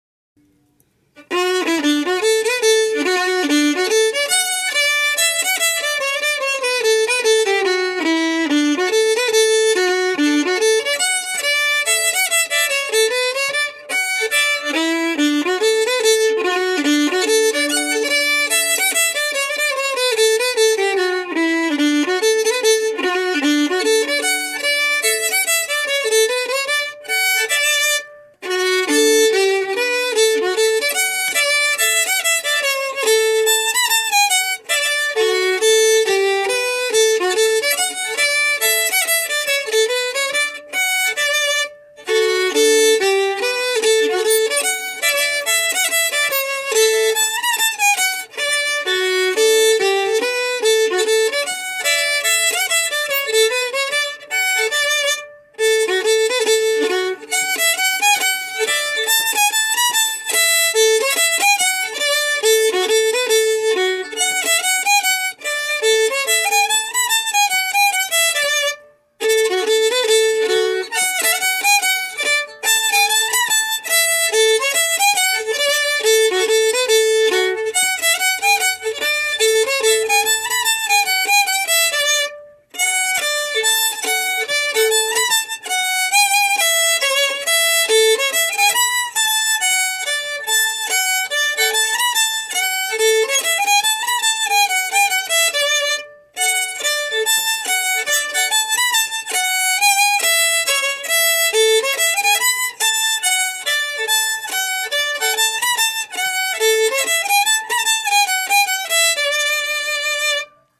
Key: D
Form: Polka
Region: Orkney